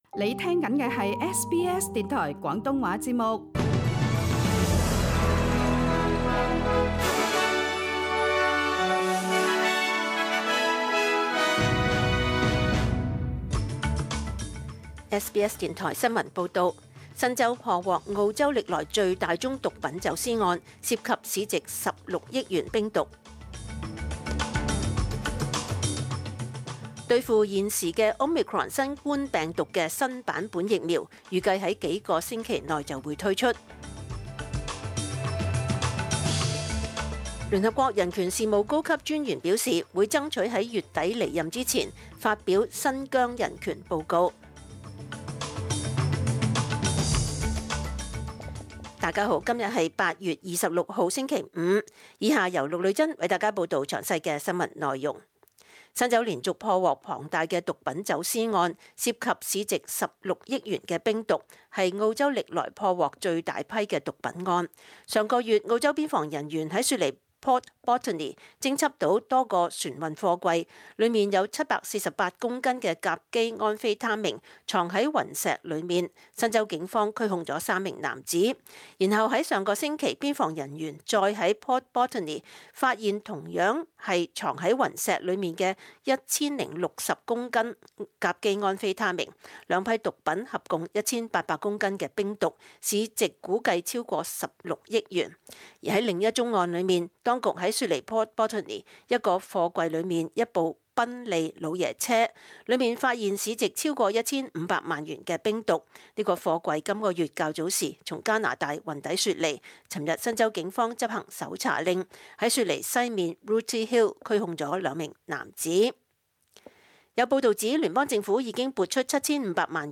SBS 廣東話節目中文新聞 Source: SBS / SBS Cantonese